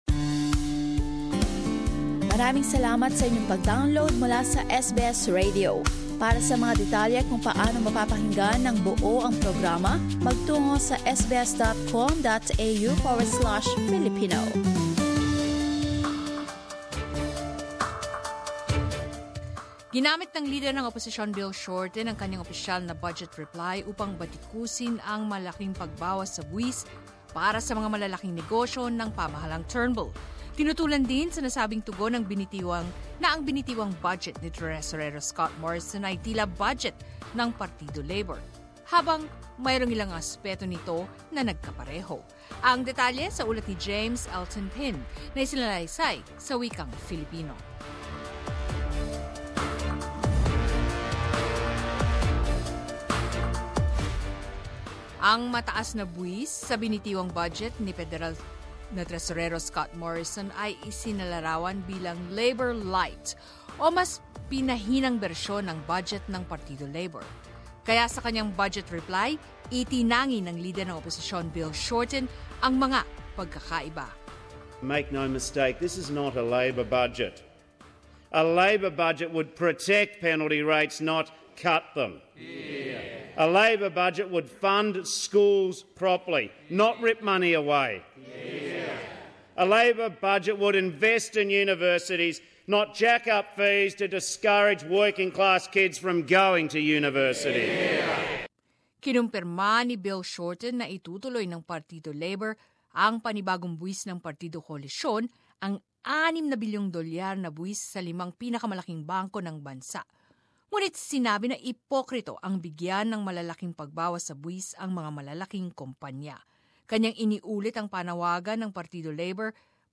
Bill Shorten delivers Budget reply speech